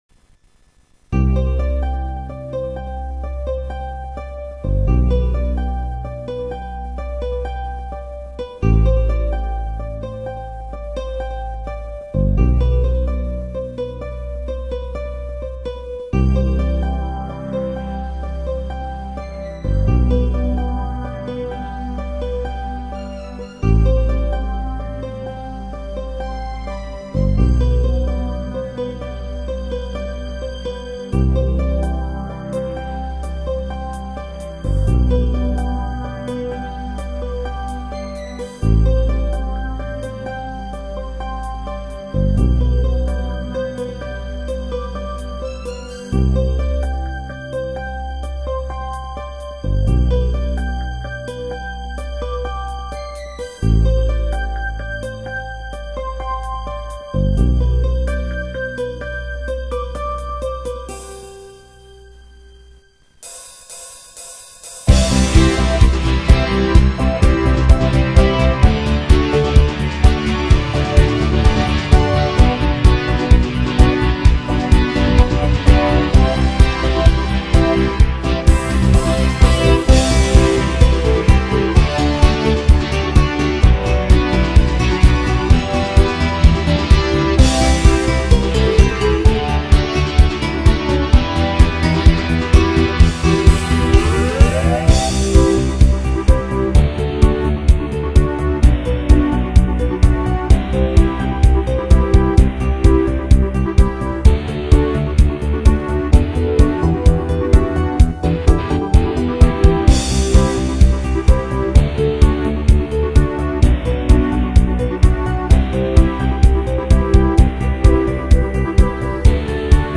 オリジナルMIDI
さて、イントロが長いです。
イントロ前半ははやぶさの孤独感を出したくていれました（というより、この辺のフレーズが最初のイメージでした）
後半部分や間奏では、スタッフや支援者の熱い思いを表現したつもりです。
データはSC-88で作りました。
対応音源：GS（SC-88推奨）